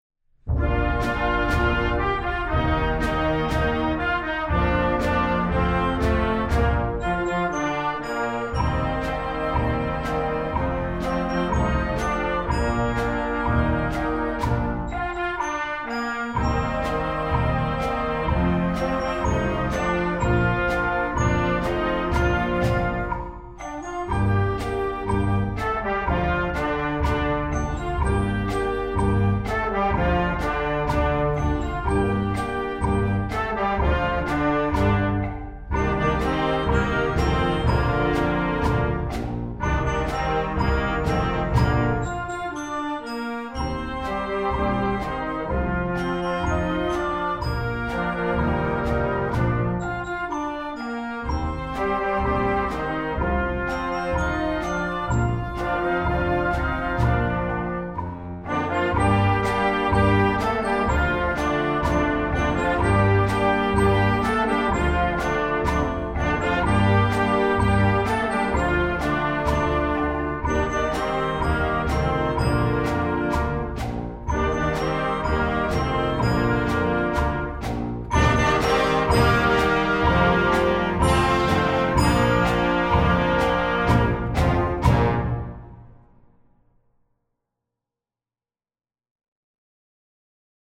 jazz, standards, instructional, children, pop, folk